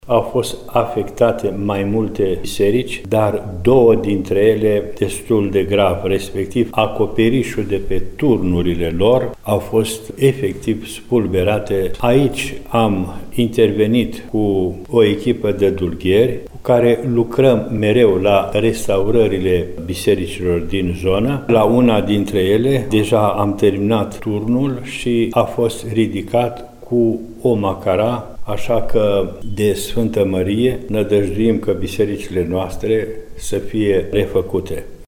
Lucrările sunt în derulare astfel ca bisericile să poată primi enoriașii cu ocazia praznicului de Sfânta Maria Mare, spune Înaltpreasfinția sa Ioan, Mitropolitul Banatului.